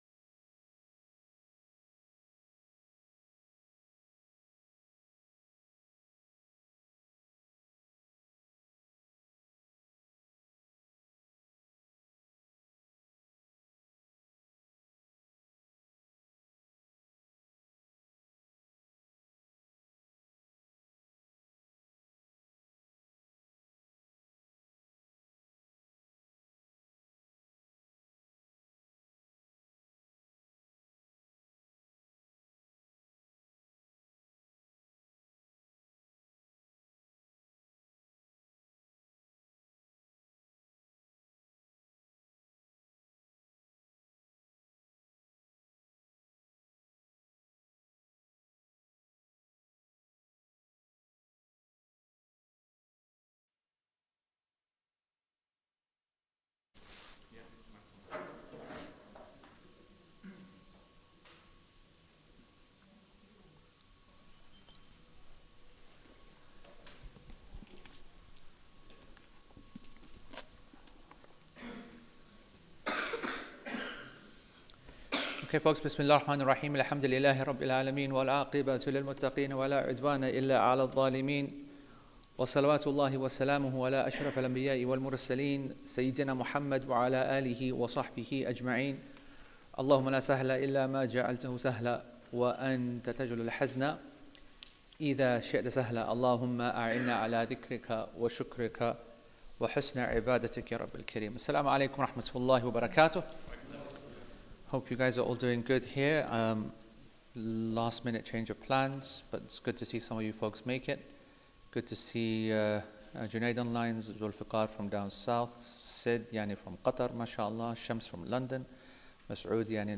Play Rate Listened List Bookmark Get this podcast via API From The Podcast A weekly class on Islamic foundational principles, theology, law and ethics based on al-Sharḥ’l-Mumti’ ‘alā Zād’l-Mustaqni’.